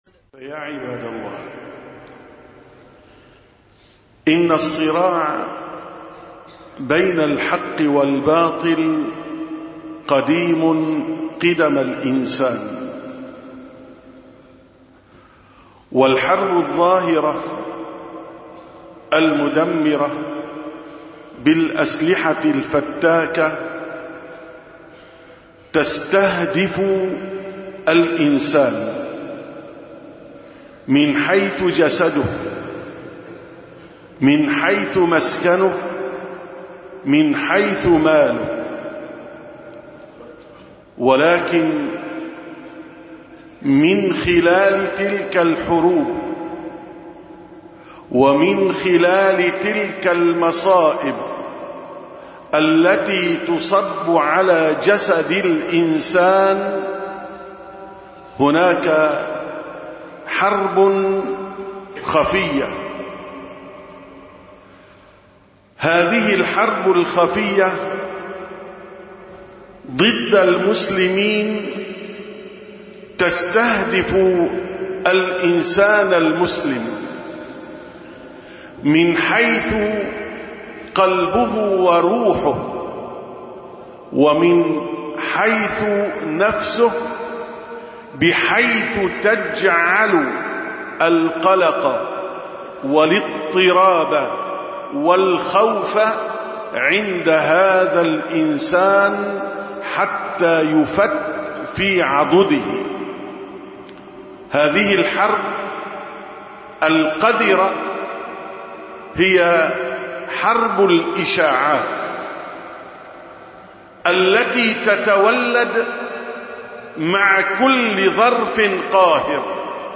856ـ خطبة الجمعة: الشائعات من أخطر الحروب المعنوية